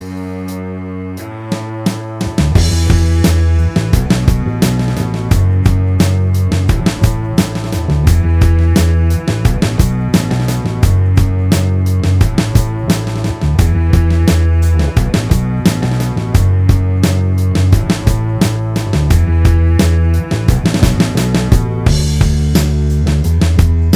Minus Lead And Solo Indie / Alternative 3:53 Buy £1.50